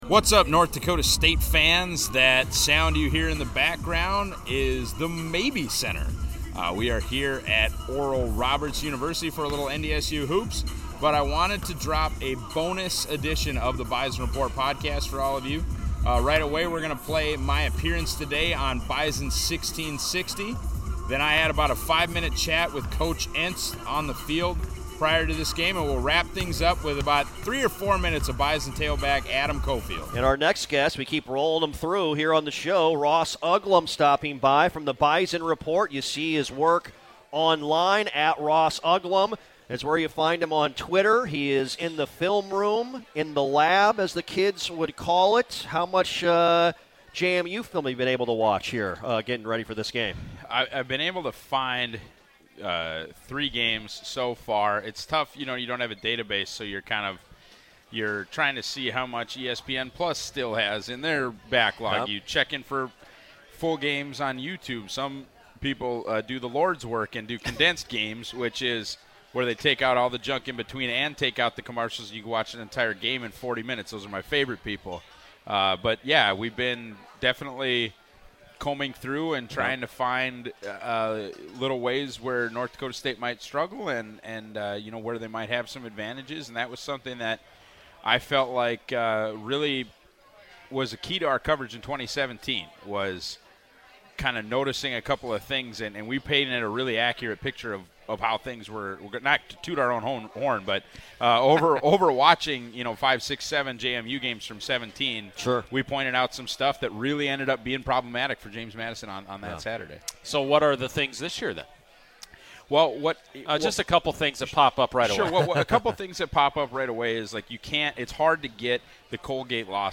interview
all from Frisco, TX.